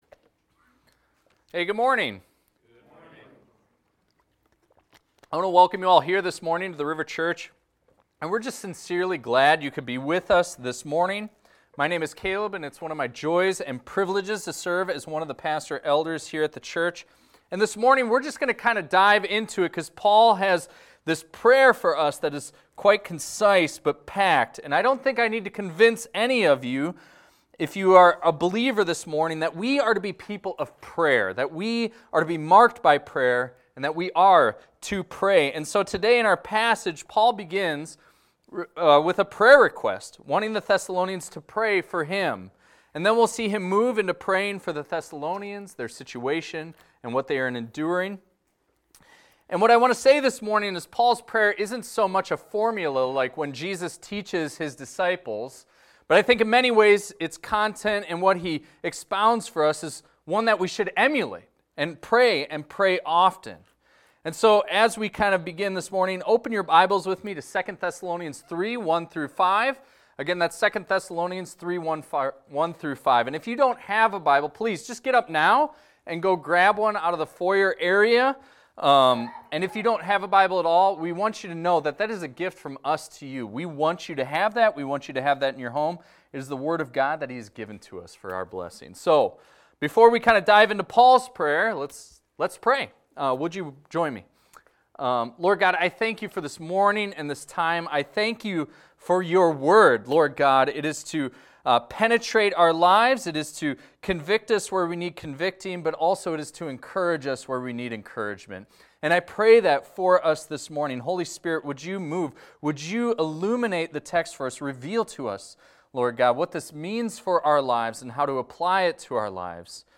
This is a recording of a sermon titled, "Pray For Us."